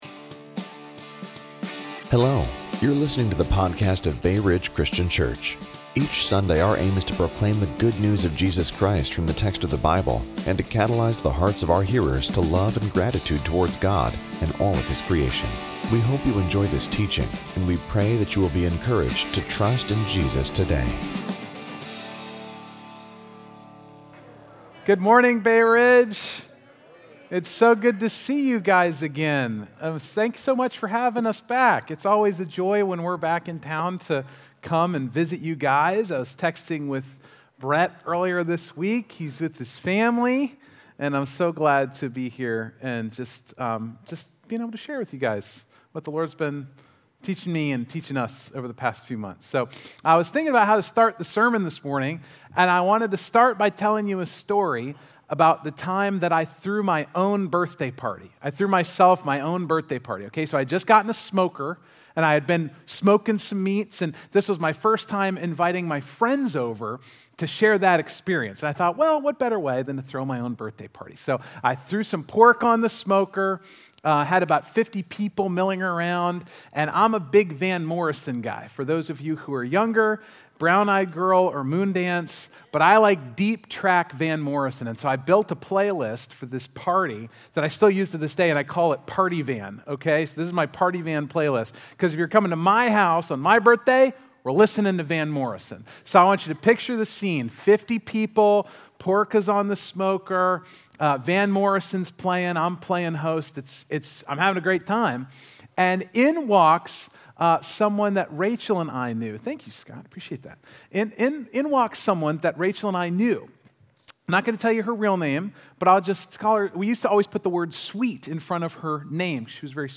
Listen to the teaching – Join us on Facebook or Youtube Live on Sunday @ 10:00 am